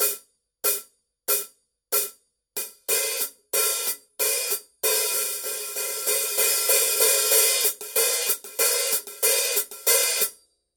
14" Hi-Hat-Becken
RUDE ist nach wie vor die erste Wahl für rohe, erbarmungslose und kraftvolle musikalische Energie in Rock, Metal und Punk.
14_sound_edge_hi-hat_pattern.mp3